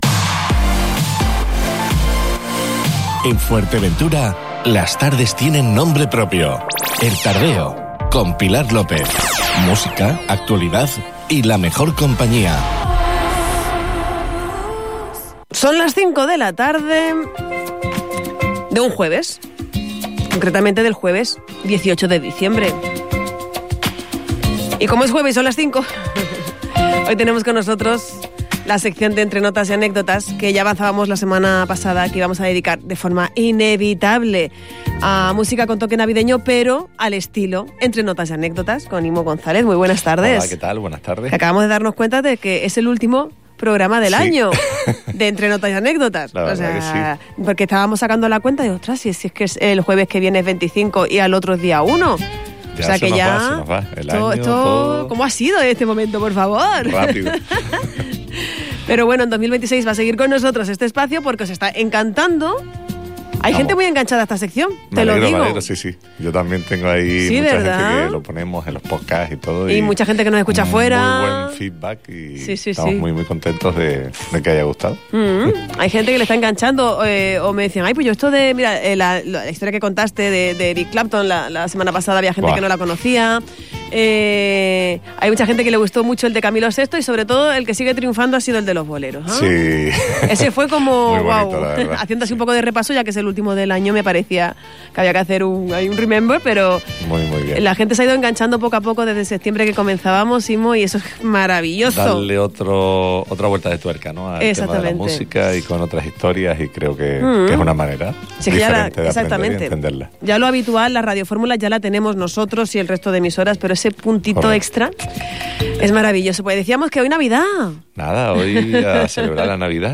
El estilo musical navideño se apodera de esta edición del espacio 'Entre Notas y Anécdotas'